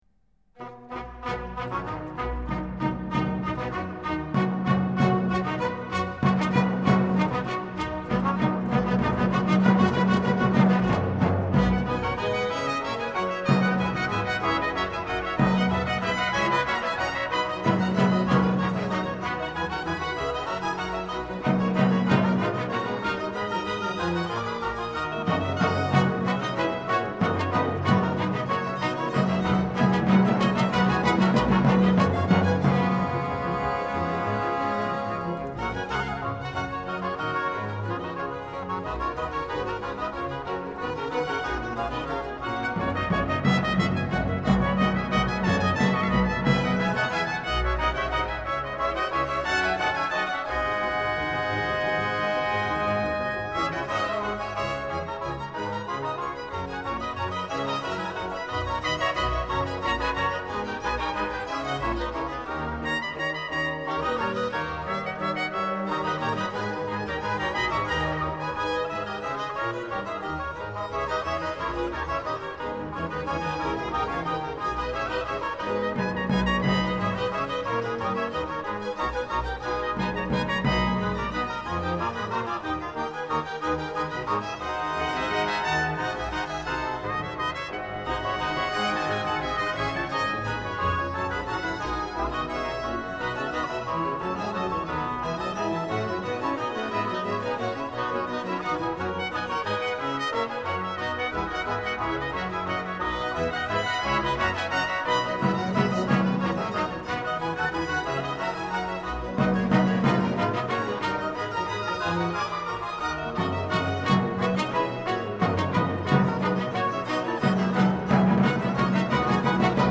1 . Sonata
Trompettes I-III, Timbales, Hautbois I-III, Taille, Basson, Violons I/II, Violes I/II, Violoncelles I/II, Continuo
Soli : S T B, Chœur : S A T B, Trompettes I-III, Timbales, Hautbois I-III, Taille, Violons I/II, Violes I/II, Violoncelles I/II, Continuo